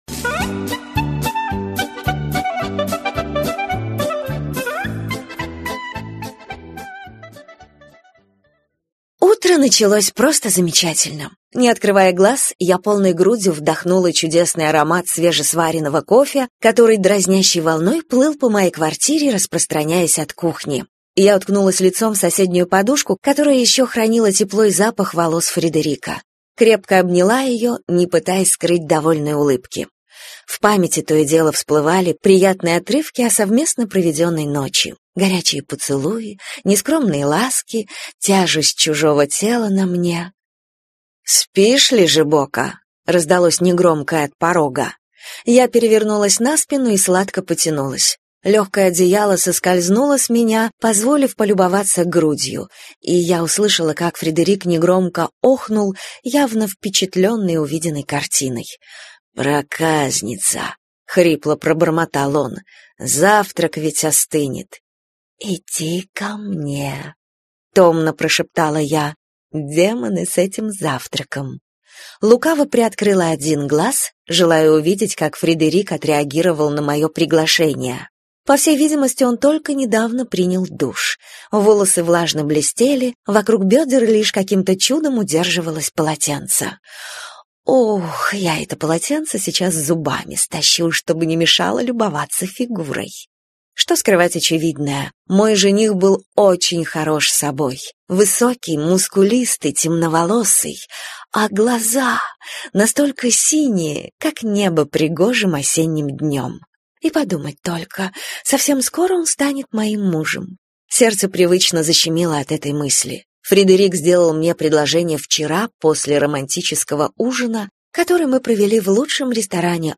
Аудиокнига Свадьбе быть!